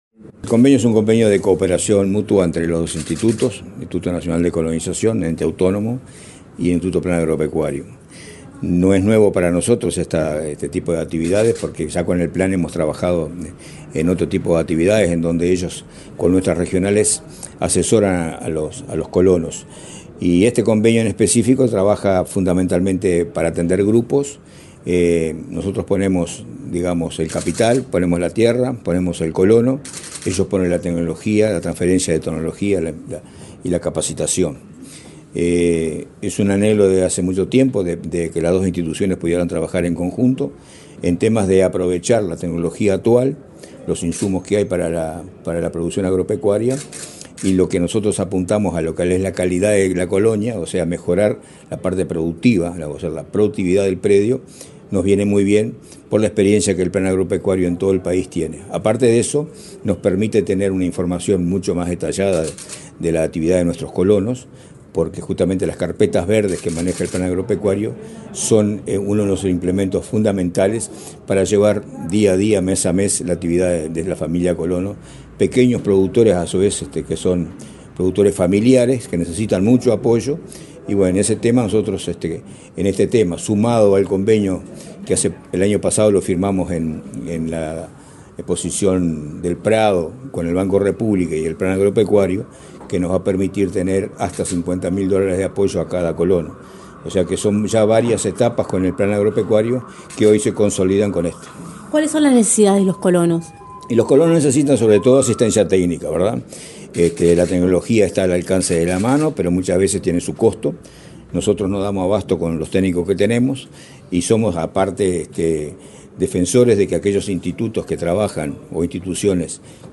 Declaraciones del presidente del Instituto Nacional de Colonización, Julio Cardozo
El presidente del Instituto Nacional de Colonización, Julio Cardozo, dialogó con la prensa, luego de firmar un acuerdo con autoridades del Instituto